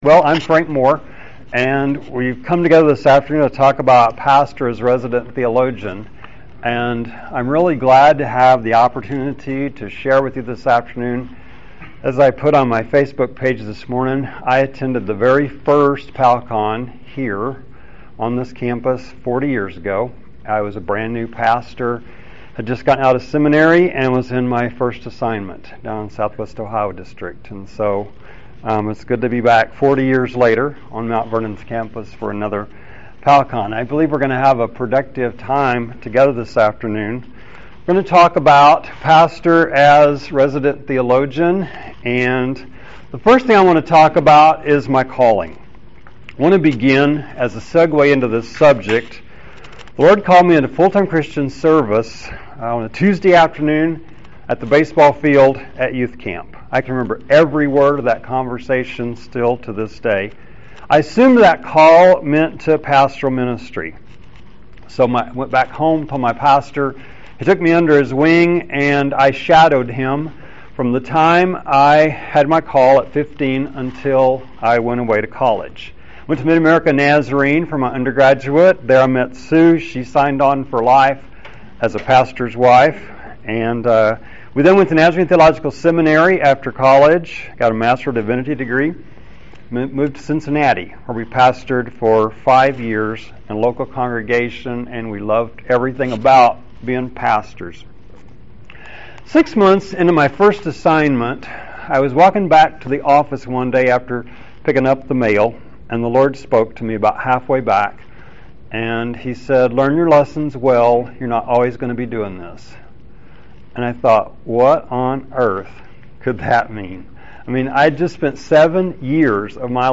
The sermons, small group studies, and conversations pastors lead shape the theological understanding of their hearers. This workshop will explore some best practices for forming a Wesleyan-holiness understanding of the Christian faith.